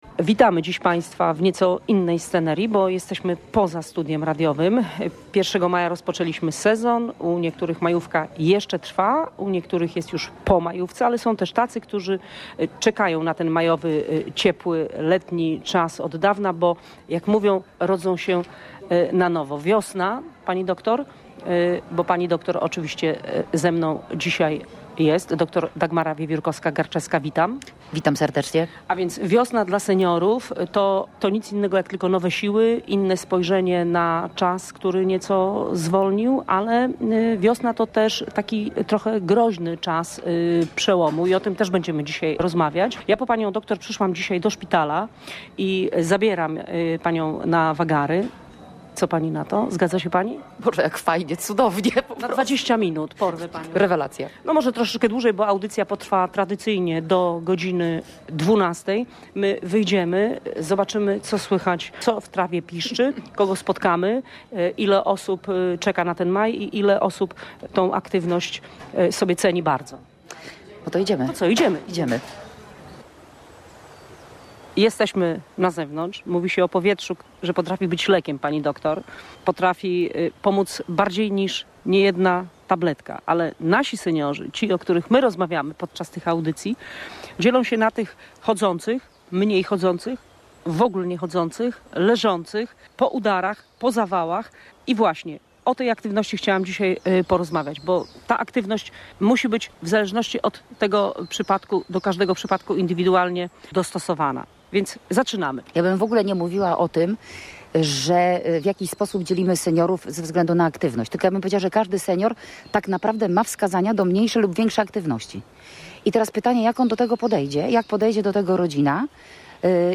– Wielu seniorom trzeba pomóc, by mogli być aktywni. Czasem wystarczy wyjście na balkon i oddychanie świeżym powietrzem – mówiła na antenie Radia Gdańsk geriatra.